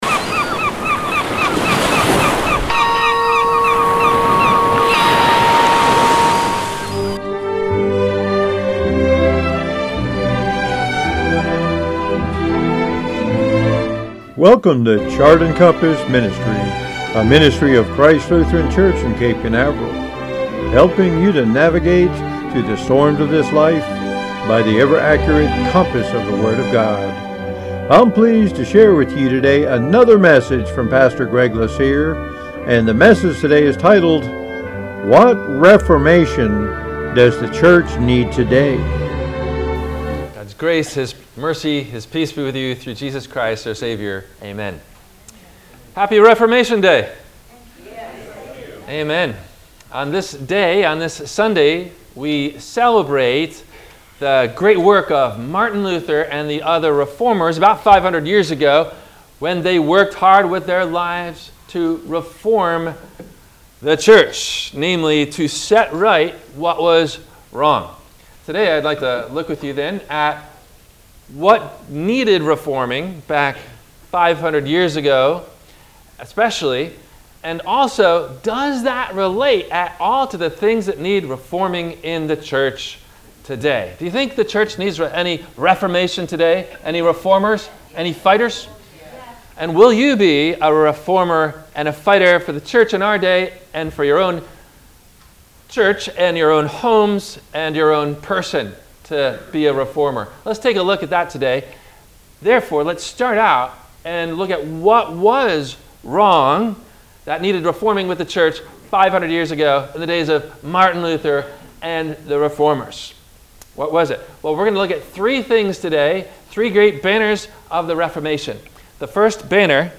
What Reformation Does The Church Need Today? – WMIE Radio Sermon – November 04 2024
No Questions asked before the Radio Message.